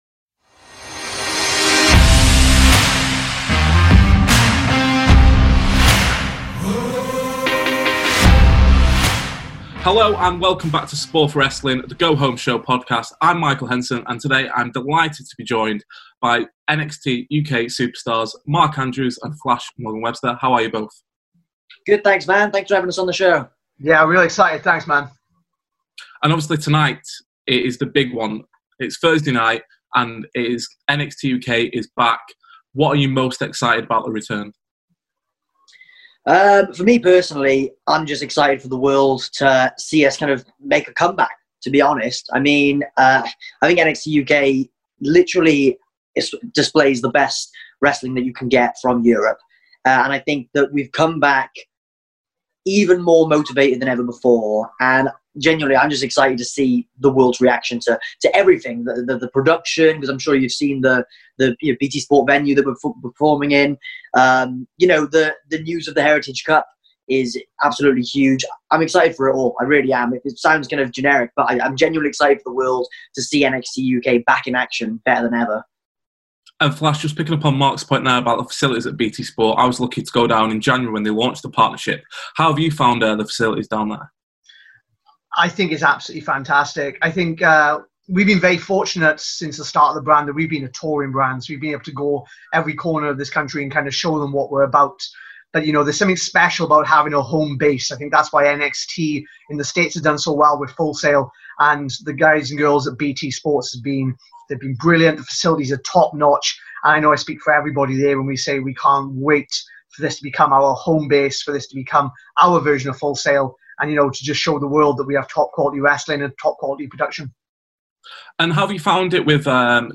This week's guests are former WWE NXT UK Tag Team Champions, Mark Andrews and Flash Morgan Webster.